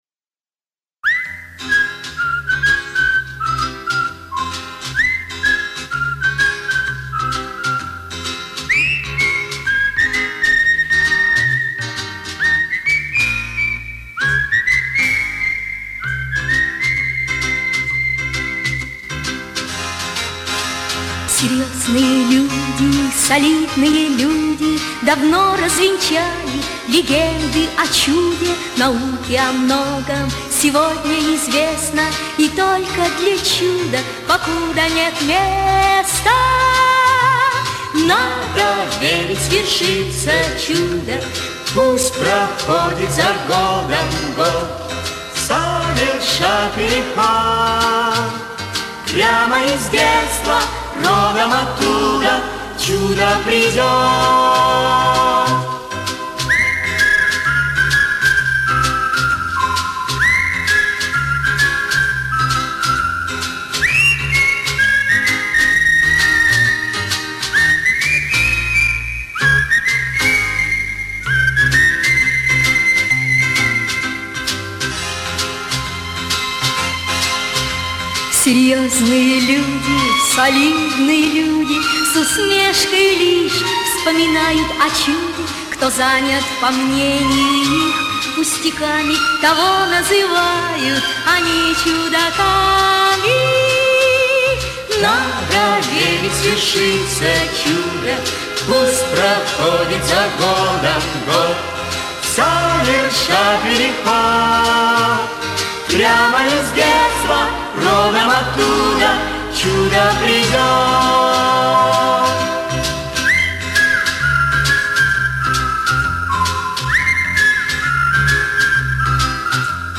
Ну, и не скрою - чуть АЧХ и стереобаланс.